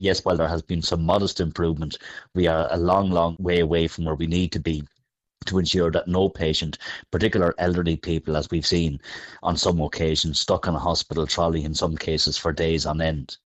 Sinn Féin’s Health Spokesperson David Cullinane says it’s not much to shout about: